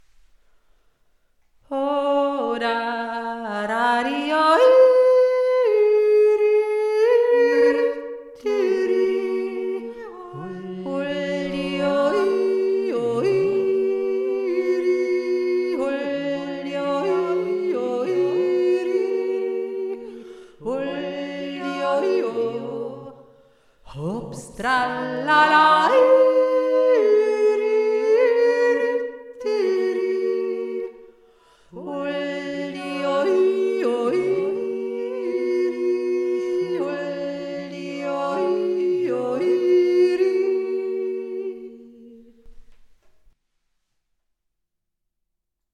Die Hautpstimme